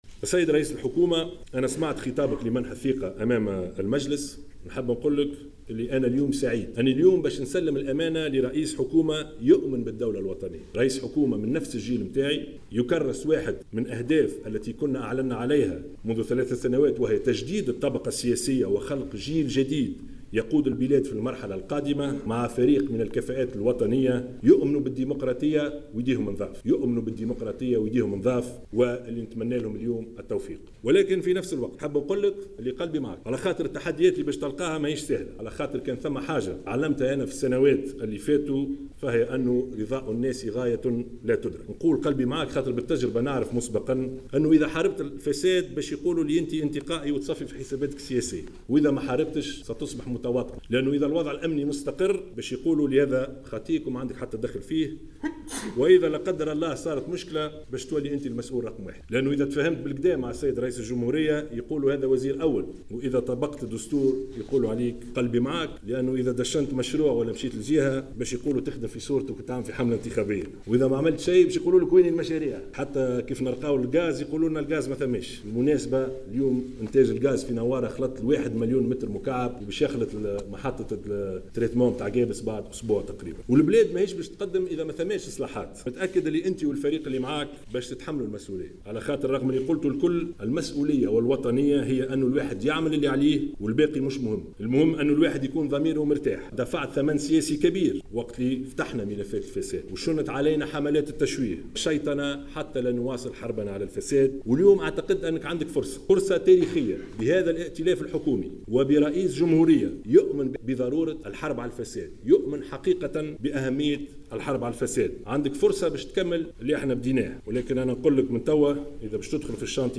قال رئيس حكومة تصريف الأعمال، يوسف الشاهد لرئيس الحكومة الجديدة، إلياس الفخفاخ خلال مراسم تسليم السلطة إن التحديات التي ستواجهه لن تكون سهلة.